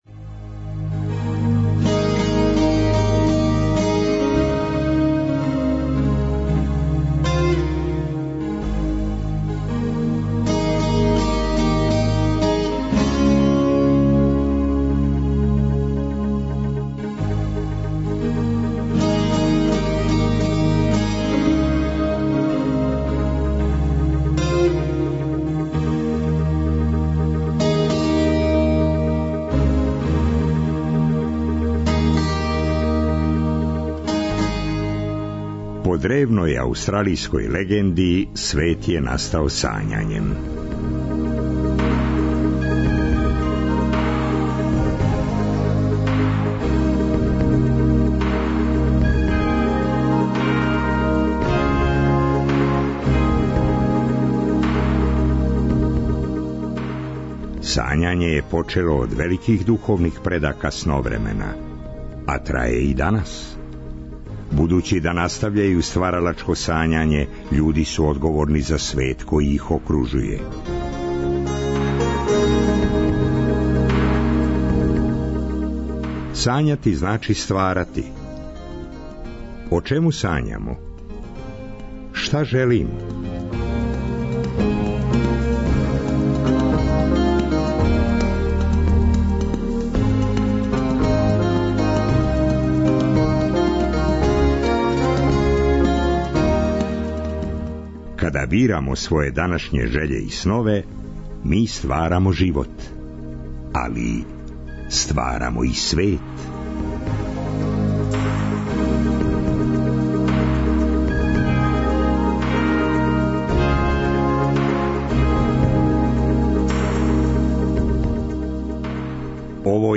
Позориште уживо у 'Сновремену'! Belgrade English Language Theatre.